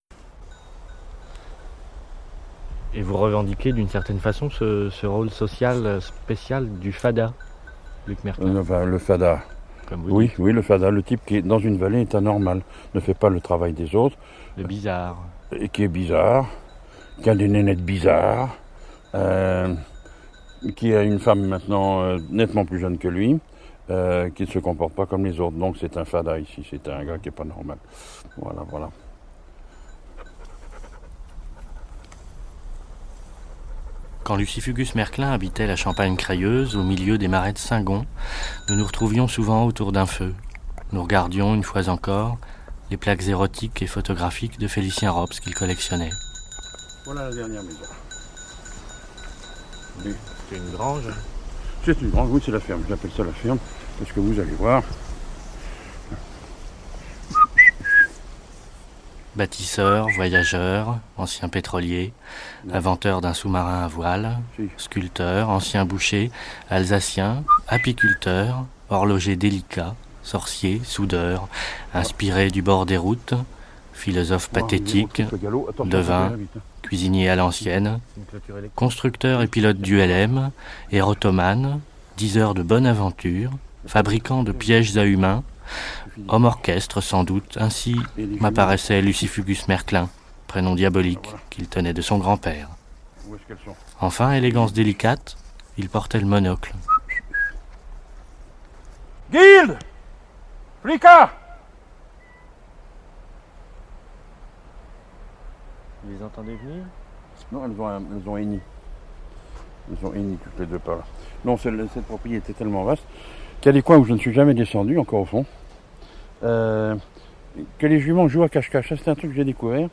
INTERWEVE